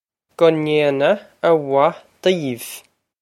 Pronunciation for how to say
Guh nain-ah ah wah deev
This is an approximate phonetic pronunciation of the phrase.